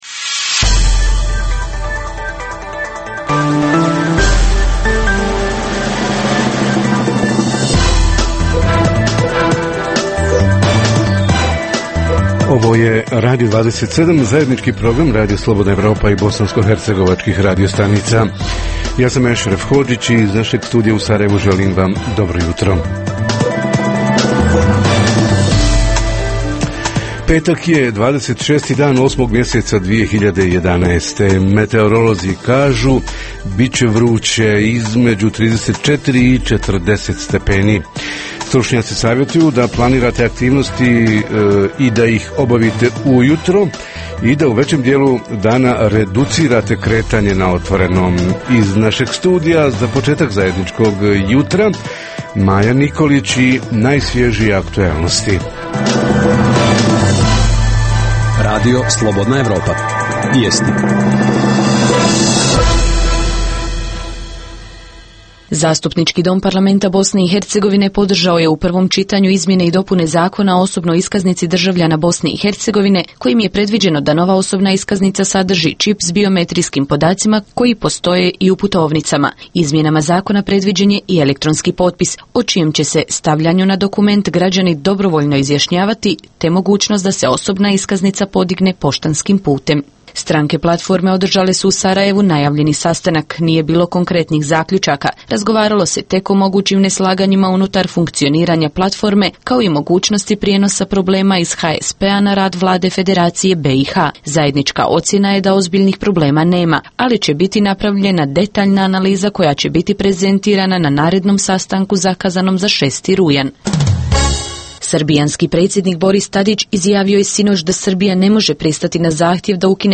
: Zelene pijace i stanje u bh. agraru – koliko je u ukupnoj ponudi domaće proizvodnje, koji je odnos ponude i potražnje, kako sve to utiče na poziciju poljoprivrednika, s jedne, i životni standard građana, s druge strane? Reporteri iz cijele BiH javljaju o najaktuelnijim događajima u njihovim sredinama.
Redovni sadržaji jutarnjeg programa za BiH su i vijesti i muzika.